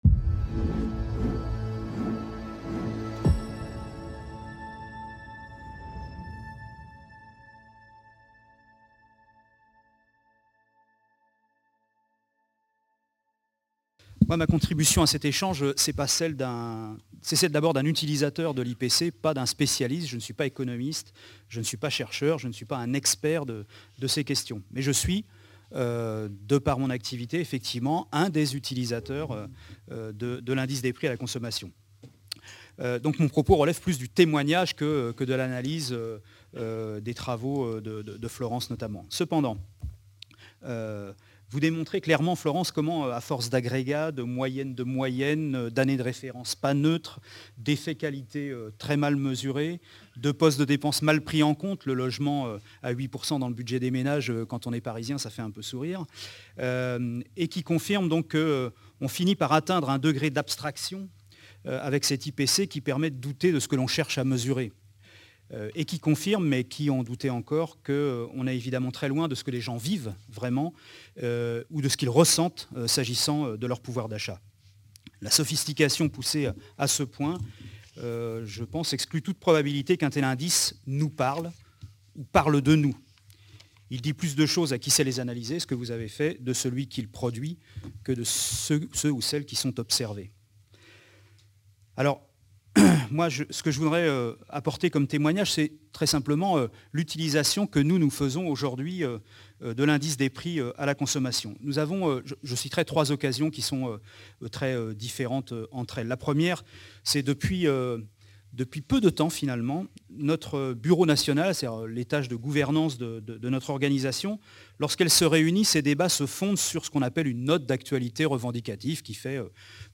La mesure de l'inflation en France : 50 ans de controverse - 2nde partie de la table ronde | Canal U